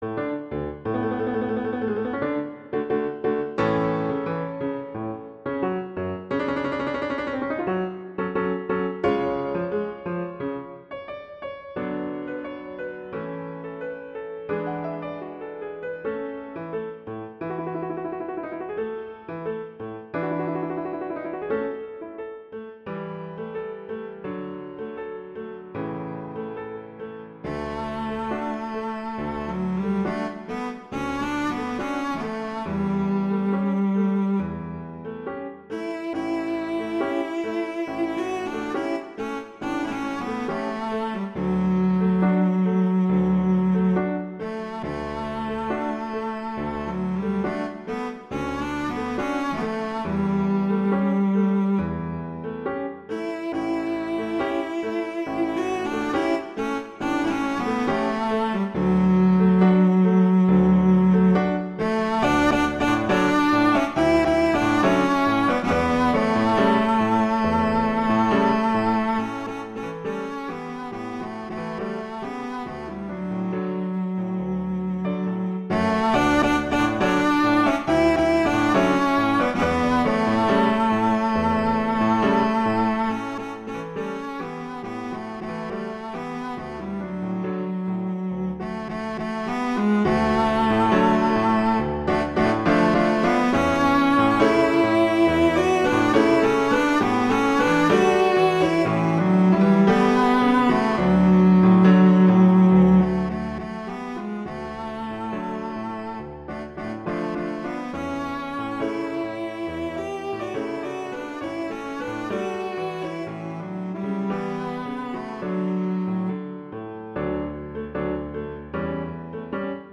classical, traditional
D major
♩=88 BPM